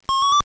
This is the Giant Rat sound from the TI-99/4A video game Tunnels of Doom.
Giant_Rat.wav